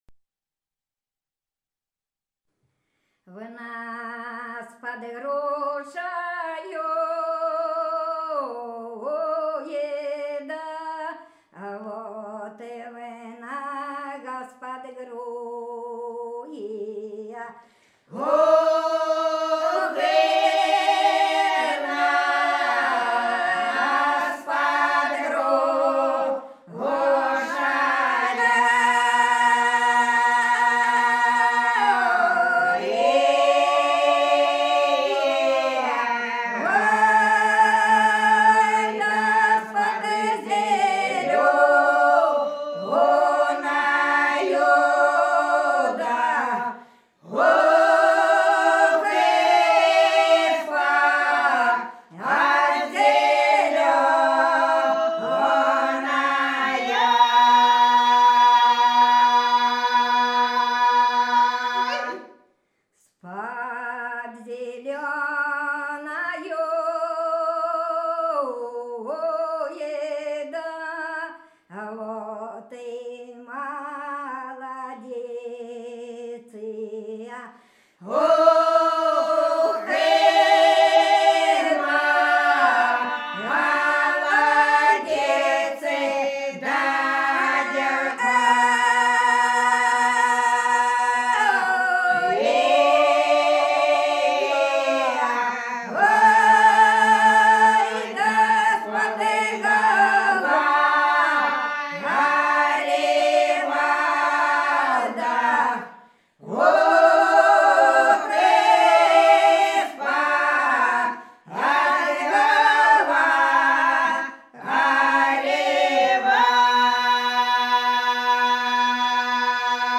Хороша наша деревня В нас под грушею - протяжная (с. Глуховка)
18_В_нас_под_грушею_-_протяжная.mp3